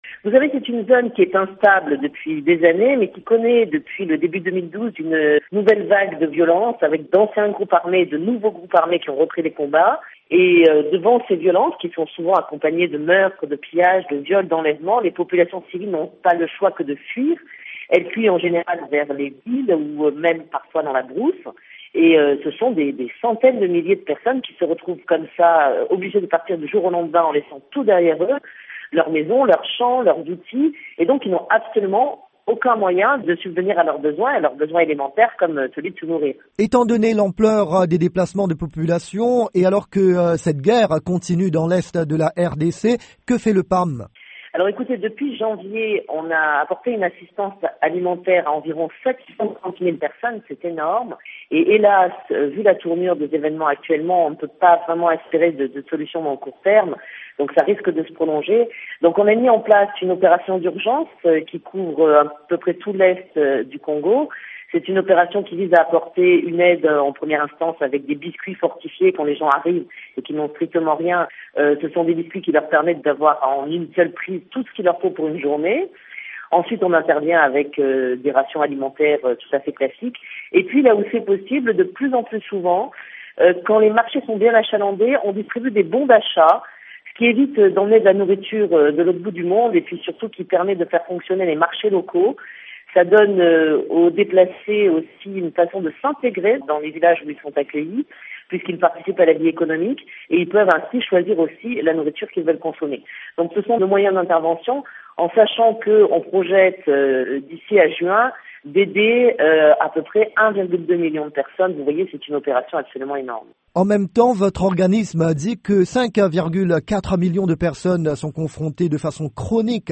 L'interview